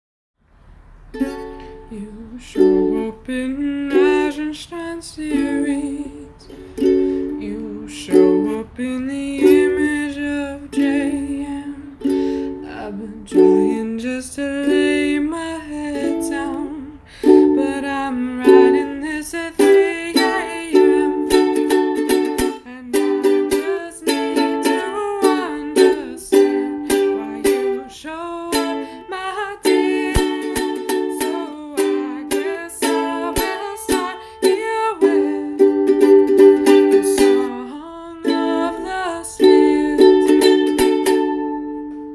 Ukulele Songs from the TamagaWHAT Seminar